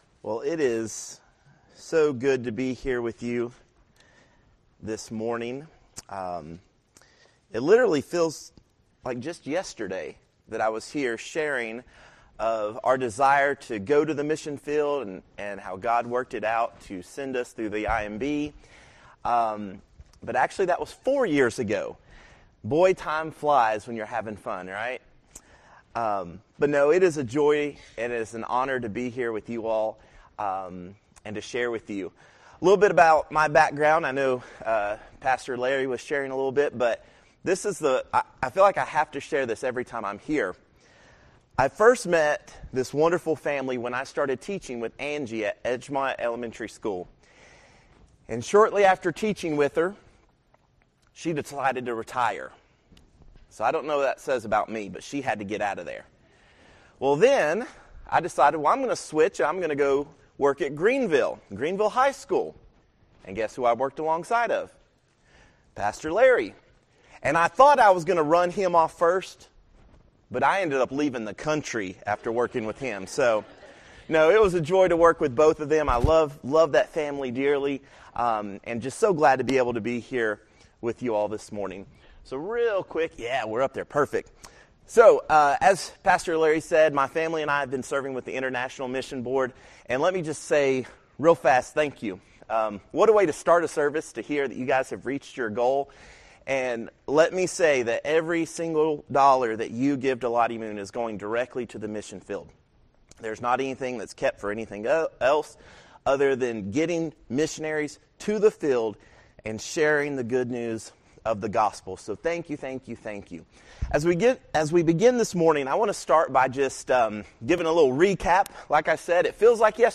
January 5, 2025 – Morning Worship